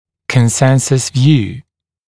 [kən’sensəs vjuː][кэн’сэнсэс вйу:]общая точка зрения, единодушное мнение